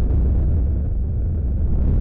fire.ogg